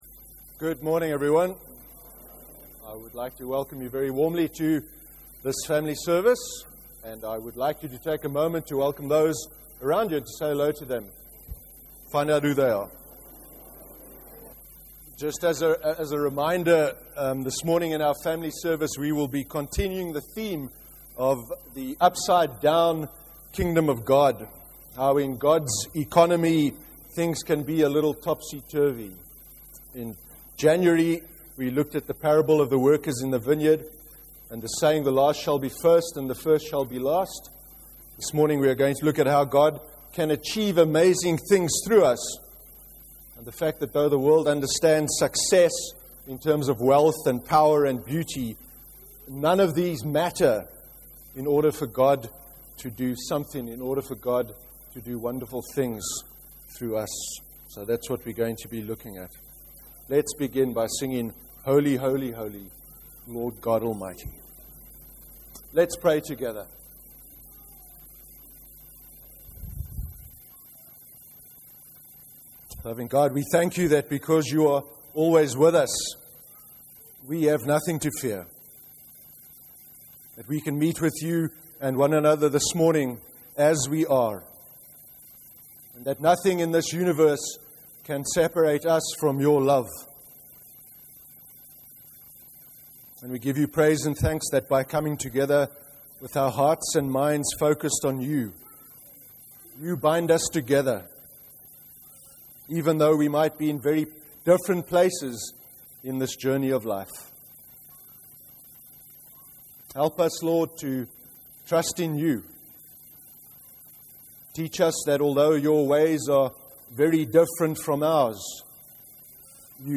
03/02/13 sermon – Gideon’s story. How God can achieve amazing things through us
In today’s family service we explored how God can achieve amazing things through us, by considering Gideon’s story.